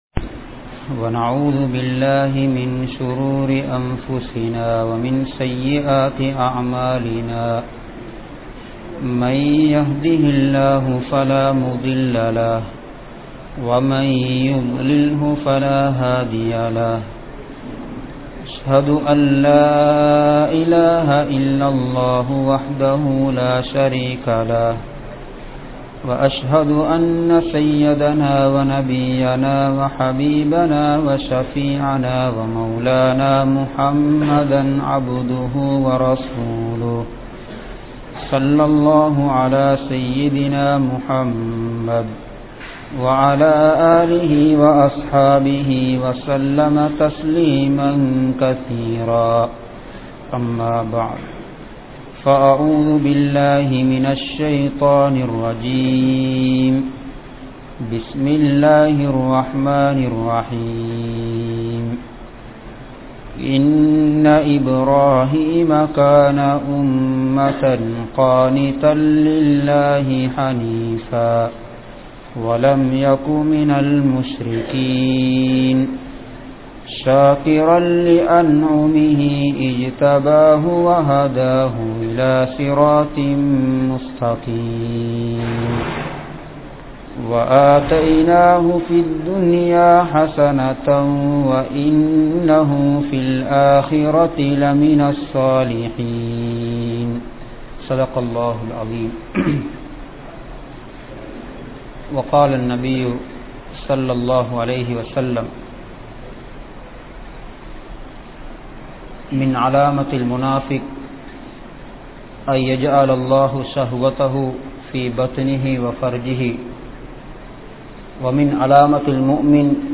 Ibrahim(Alai)Avarhalin 07 Thanmaihal (இப்றாஹீம்(அலை) அவர்களின் 07 தன்மைகள்) | Audio Bayans | All Ceylon Muslim Youth Community | Addalaichenai